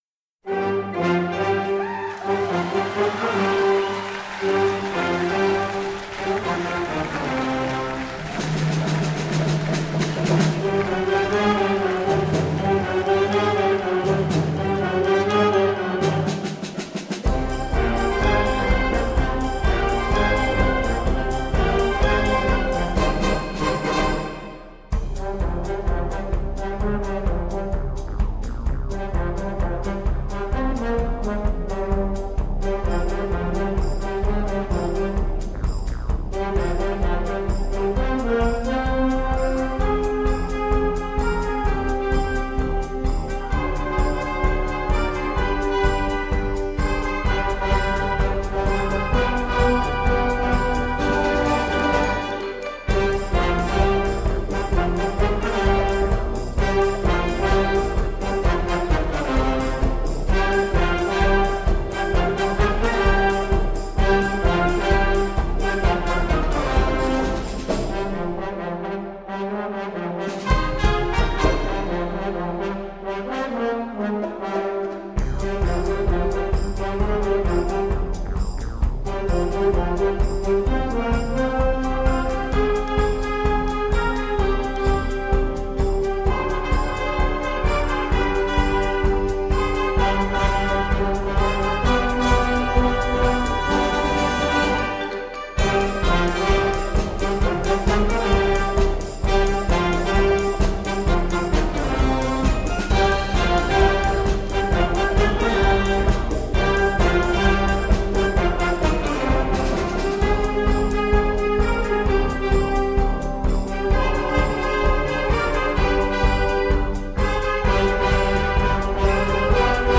Here cometh the modern dance-beat!